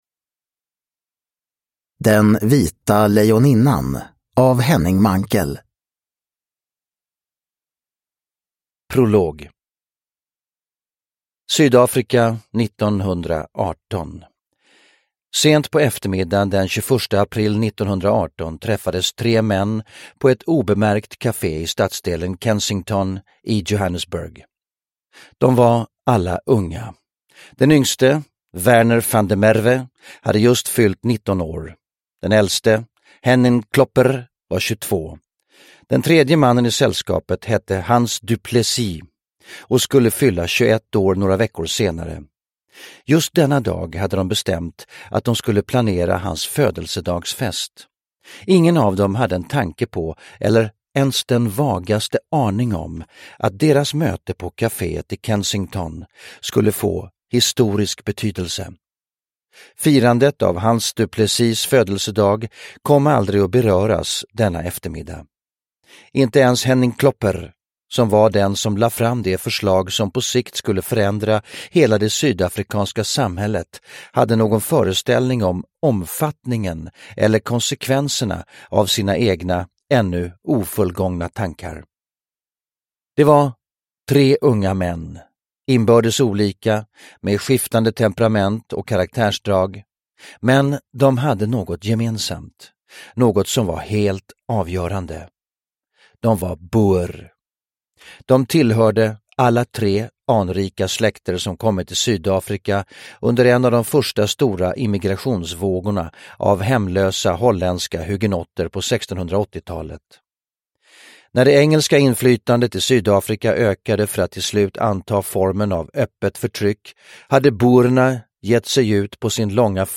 Den vita lejoninnan – Ljudbok
Här i Stefan Sauks originalinspelning.
Uppläsare: Stefan Sauk